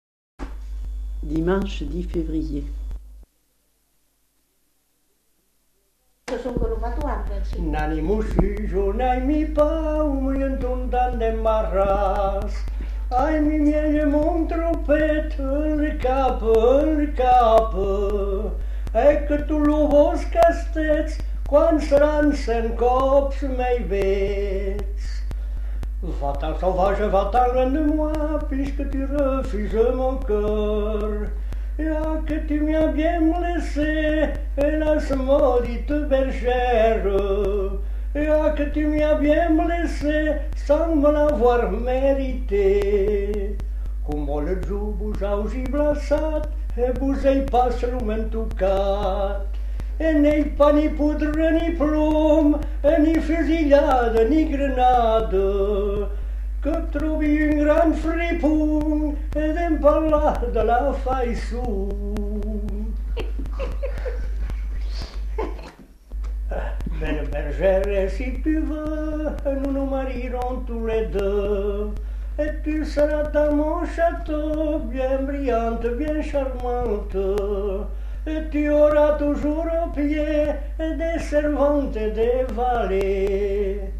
Aire culturelle : Agenais
Genre : chant
Effectif : 1
Type de voix : voix d'homme
Production du son : chanté